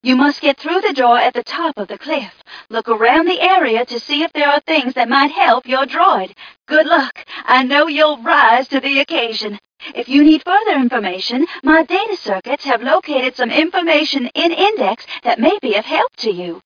1 channel
mission_voice_t4ca001.mp3